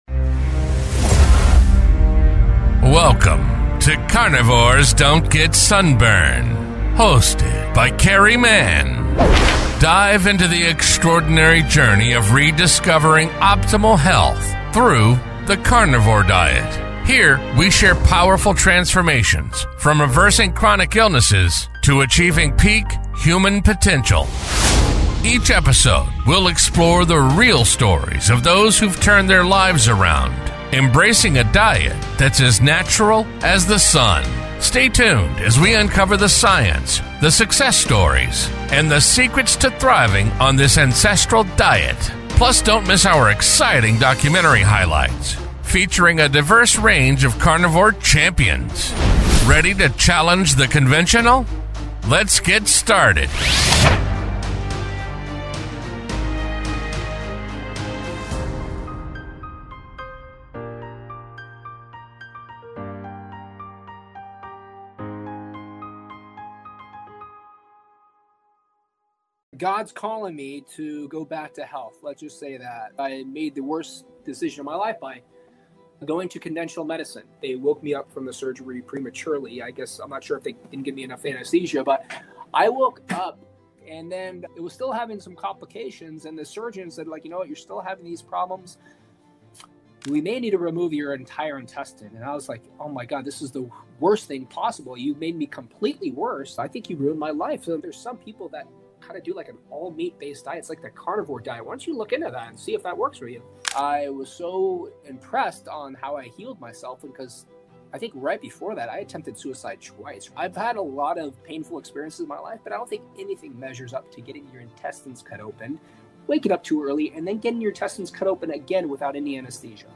In this powerful interview, he shares how conventional medicine nearly ruined his life, and how meat, faith, and truth helped him take it back.